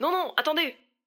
VO_ALL_Interjection_02.ogg